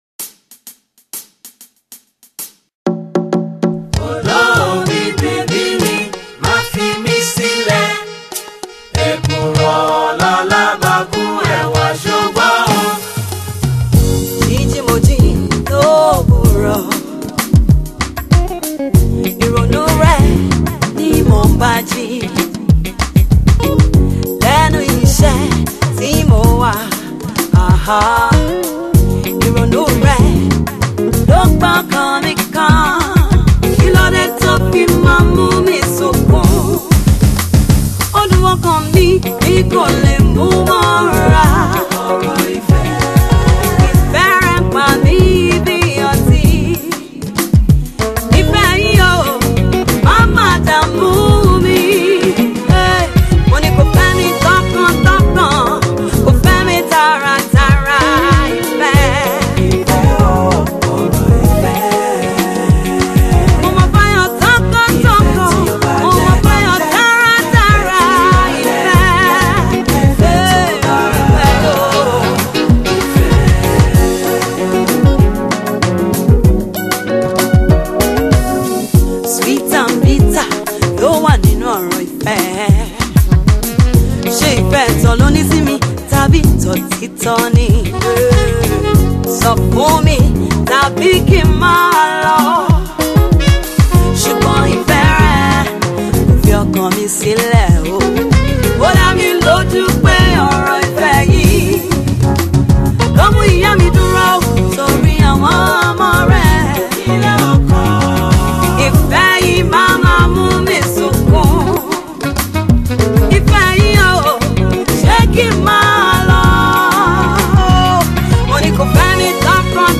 it’s no surprise that her vocals lean towards the soulful.
a Yoruba song